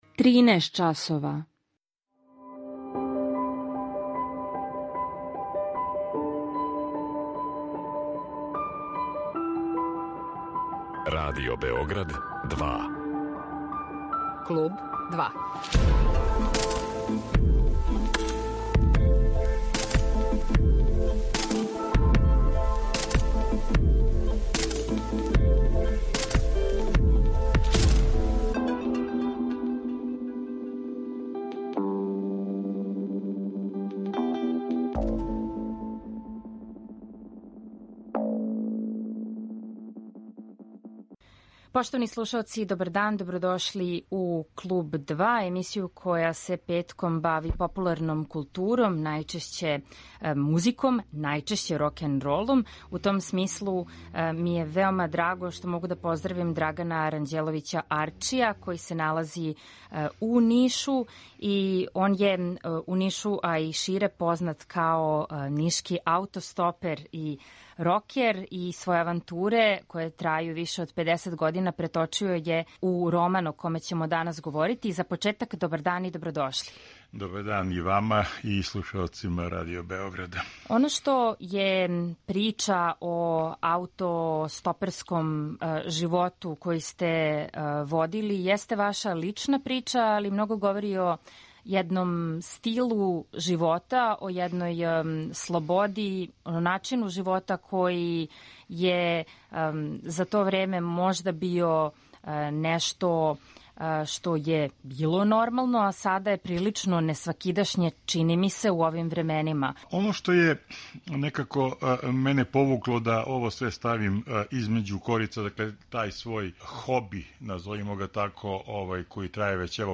Наравно, у емисији ћемо слушати и музику коју гост воли и која се и помиње у његовој књизи, коју је објавио Простор за креативно деловање ДЕЛИ из Ниша.